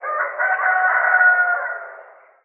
rooster_crowing_01.wav